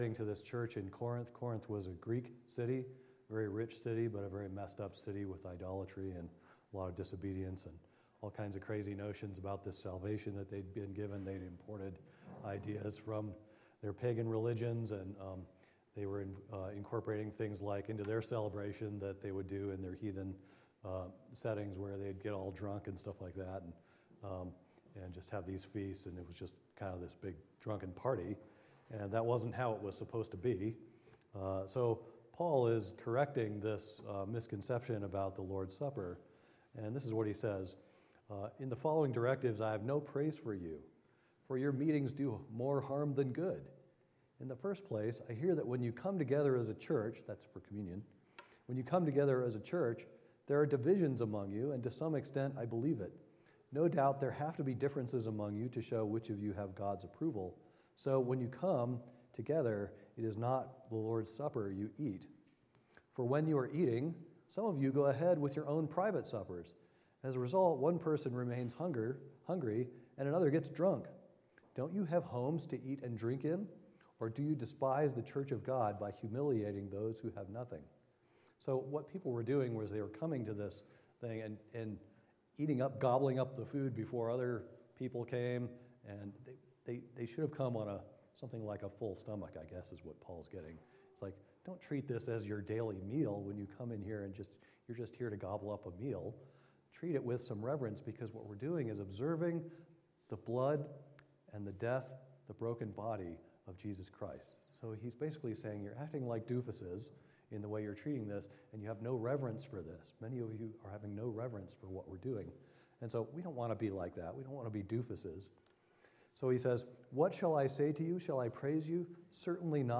Sunday Service Service Type: Midweek Meeting « The Unfathomable Goodness of God What is the Kingdom of God Part 2